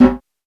PERC.10.NEPT.wav